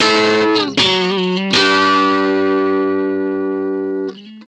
ロック風のアコースティックギターサウンド。